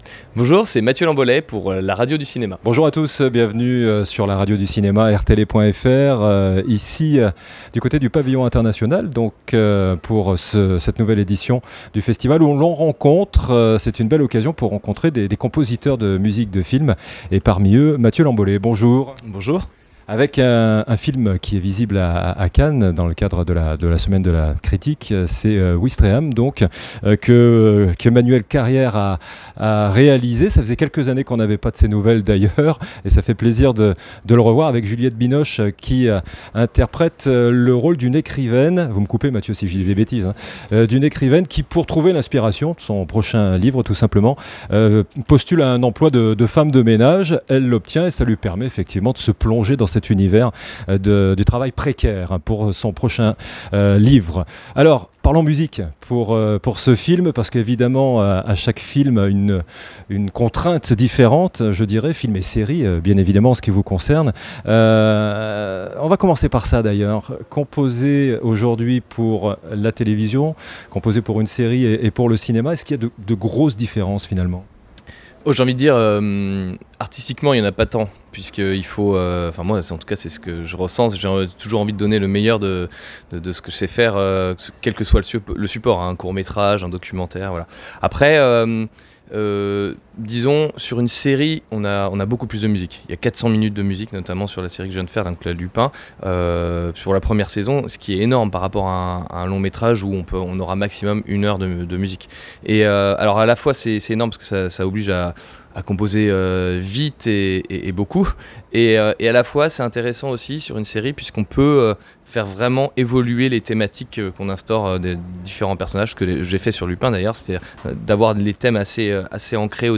Rencontre à Cannes 2021: Mathieu Lamboley
Mathieu Lamboley, compositeur membre de la SACEM